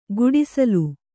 ♪ guḍisalu